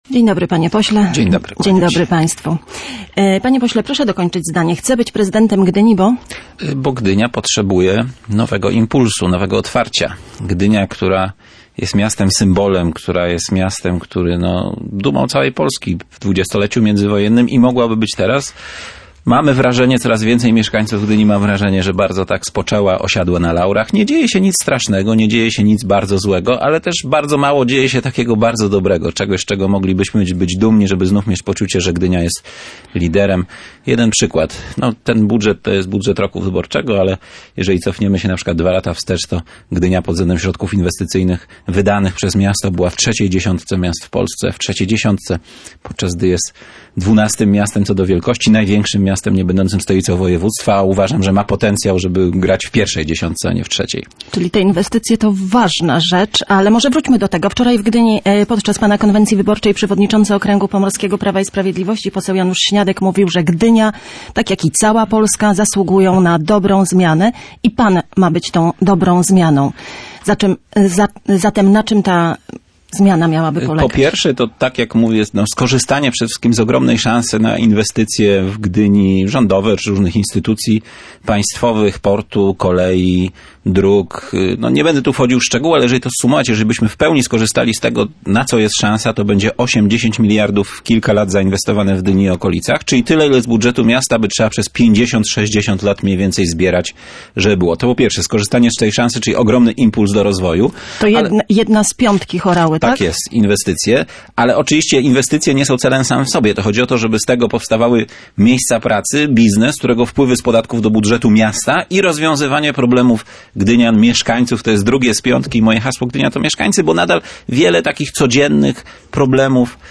W poniedziałek był Gościem Dnia Radia Gdańsk. Zdaniem Marcina Horały, budżet Gdyni jest nieadekwatny do wielkości i możliwości miasta.